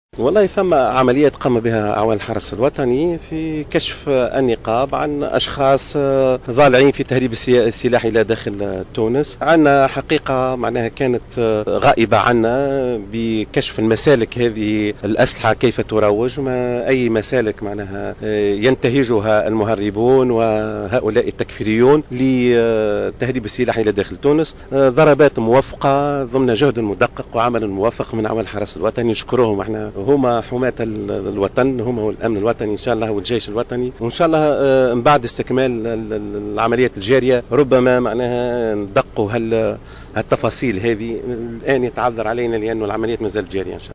أكد وزير الداخلية لطفي بن جدو على هامش اشرافه على انعقاد مجلس الأمن الجهوي بسيدي بوزيد اليوم الإثنين 20 اكتوبر 2014 وجود عمليات جارية لكشف النقاب عن مسالك تهريب الأسلحة وكيفية ترويجها وعدد من الضالعين في تهريب السلاح.